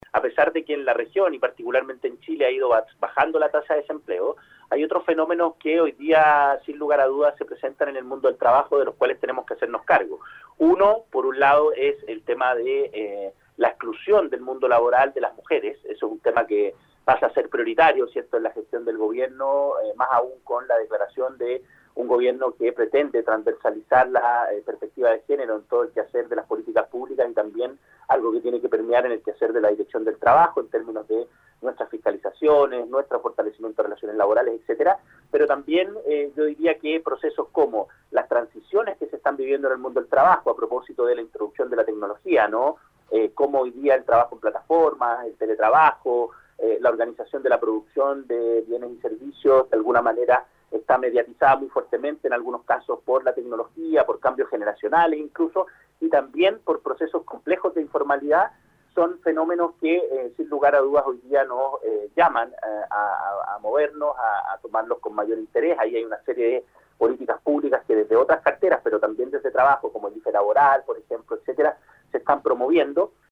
En entrevista con Nuestra Pauta, la autoridad indicó que “el compromiso de nuestro gobierno es con el trabajo decente, seguro y con igualdad de oportunidades”.